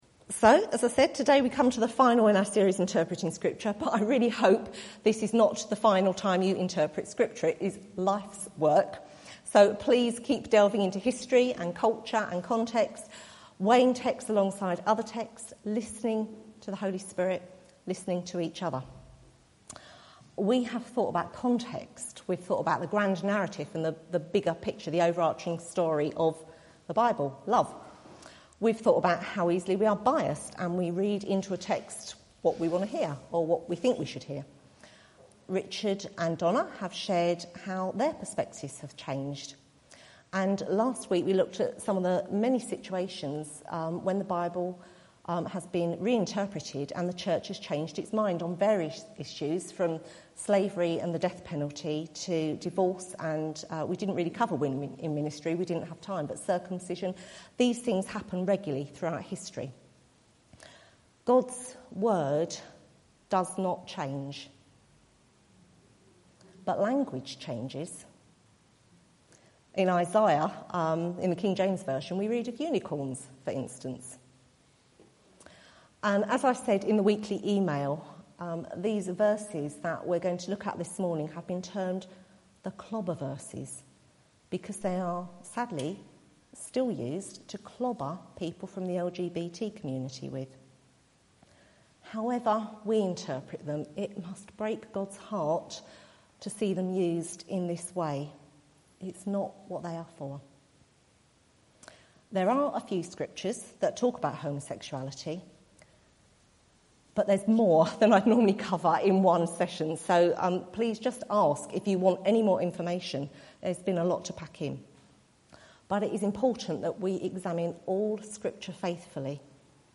Series: Interpreting Scripture Service Type: Sunday Morning